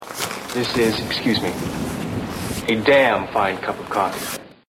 Add a new commentary - a "voiceover" on a new track.
Just mix the two together - your voiceover louder and the wind sound in the background.
Here's an example - your Wind Wave file mixed with a voice.